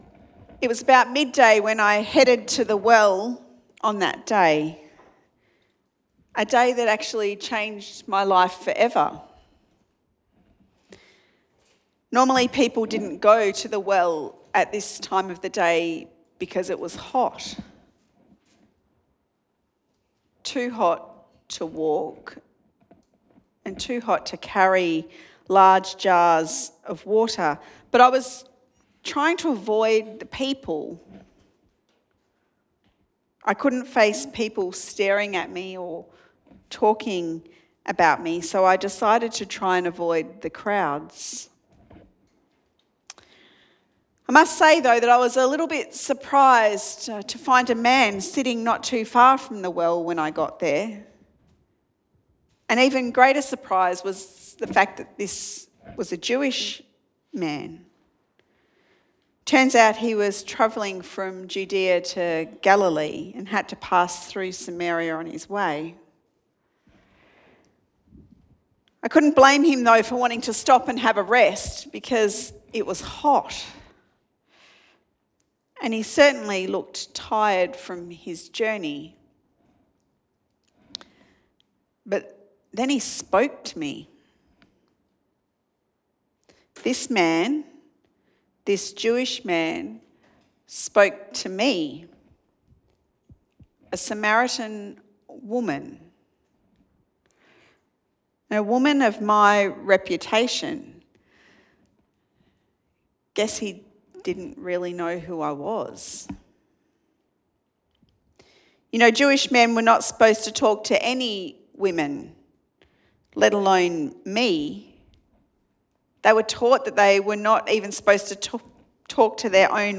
Sermon Podcasts Jesus & Justice